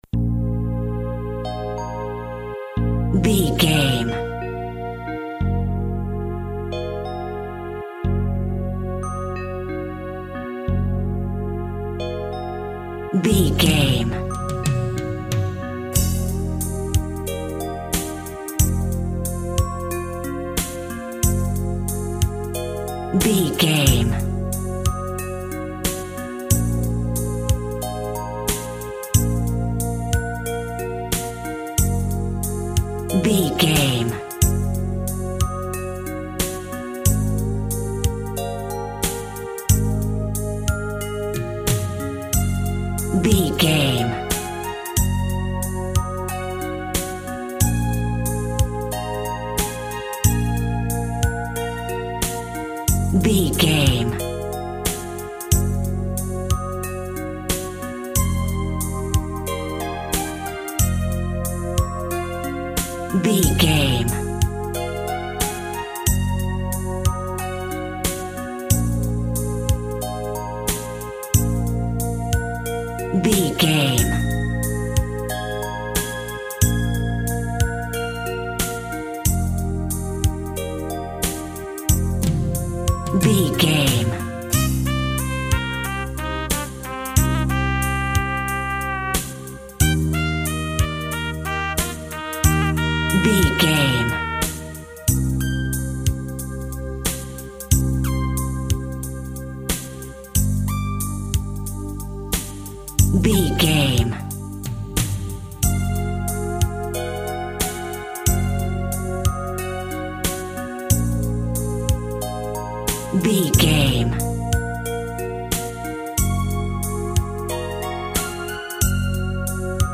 Eighties Ballad Music Cue.
Aeolian/Minor
B♭
Slow
hypnotic
dreamy
tranquil
smooth
drums
synthesiser
bass guitar
electronic
synth bass